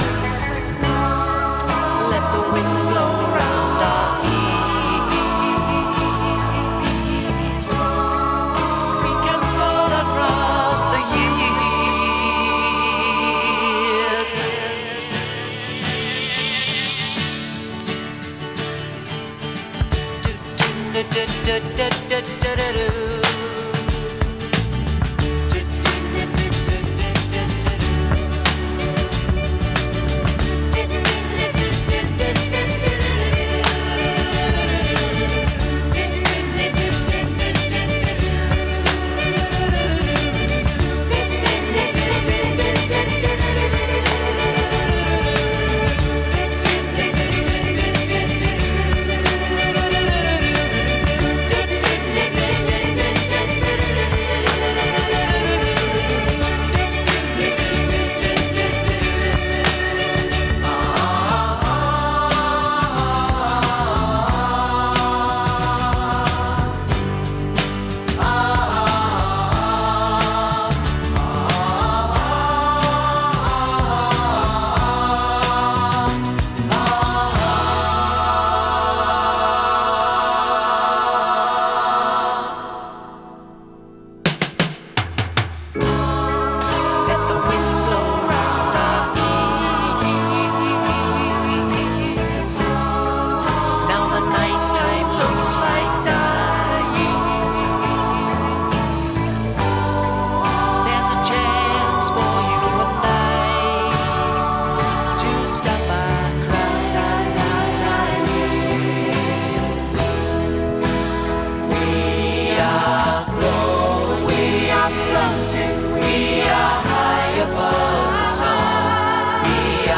UKの男女混声6人組グループのサードLP。
美しいコーラスにドラマチックでヒネったアレンジが素晴らしい英国ポップです！